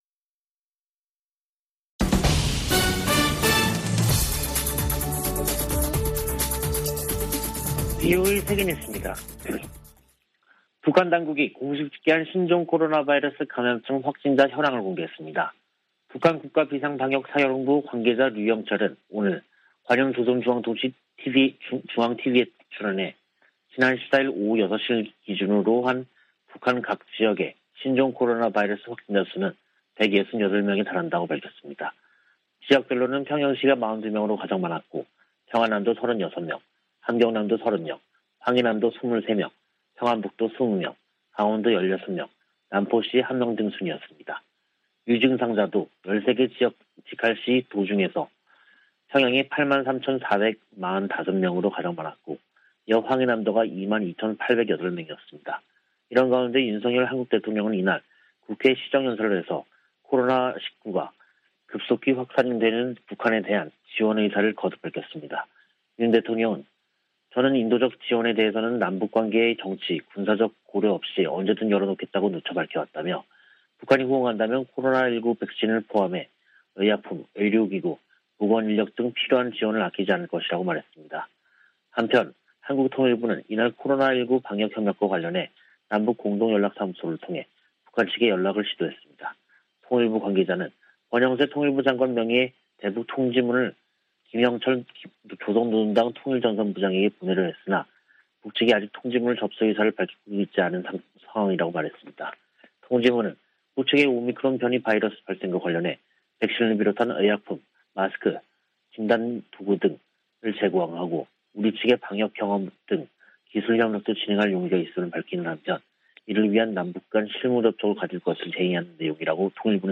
VOA 한국어 간판 뉴스 프로그램 '뉴스 투데이', 2022년 5월 16일 3부 방송입니다. 북한에서 신종 코로나바이러스 감염병이 폭증한 가운데, 윤석열 한국 대통령은 백신 등 방역 지원을 아끼지 않겠다고 밝혔습니다. 미 국무부는 한국 정부의 대북 백신 지원 방침에 지지 입장을 밝히고, 북한이 국제사회와 협력해 긴급 백신 접종을 실시할 것을 촉구했습니다. 미국과 아세안은 특별정상회의에서 채택한 공동 비전성명에서 한반도의 완전한 비핵화 목표를 확인했습니다.